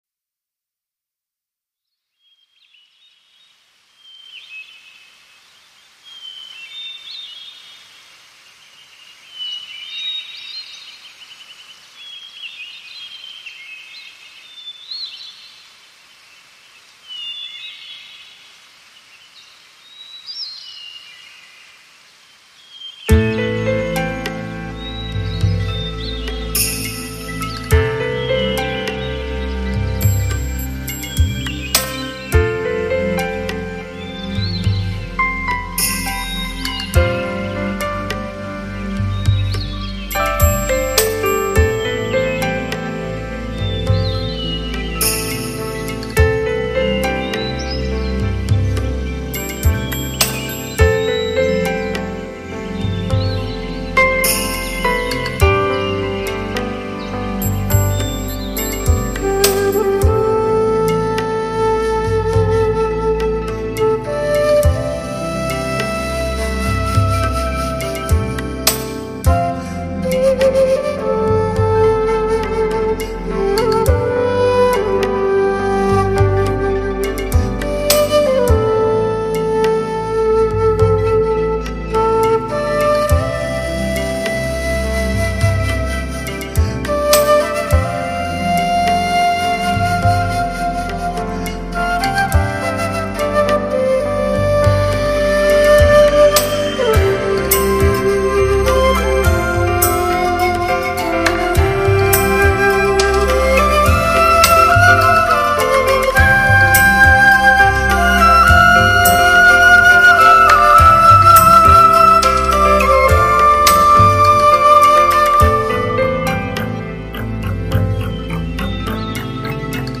DTS-5.1声道
在这个充满神秘气息的森林殿堂里，侧耳倾听，有着最原始的乐器所敲击来的独特声响，有着最纯粹的韵律所奏响的音乐盛宴。